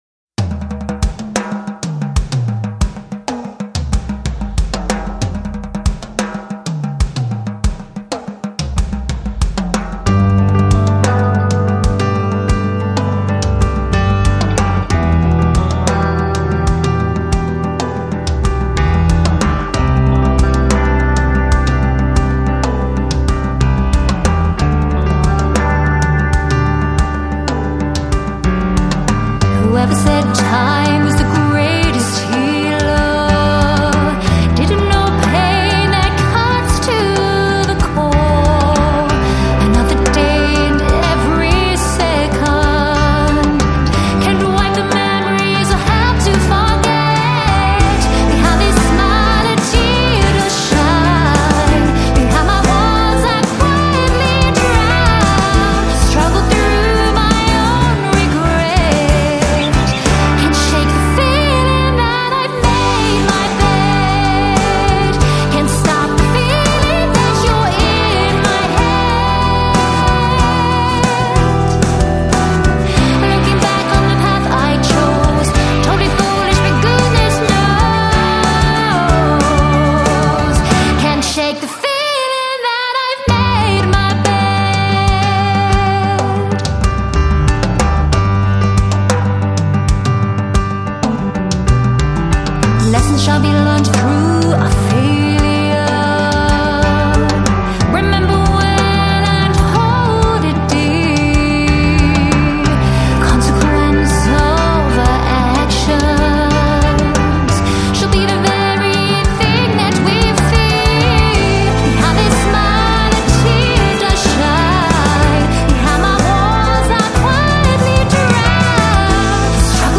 Жанр: Hard Rock | Pop Rock
它的声音突出了音乐的纯洁和美丽。